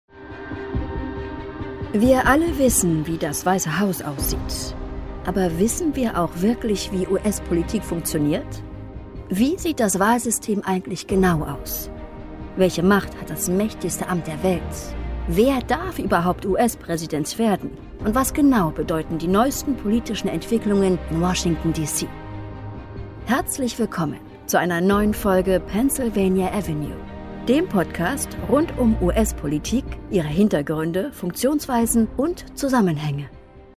Imagefilm